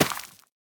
assets / minecraft / sounds / item / plant / crop6.ogg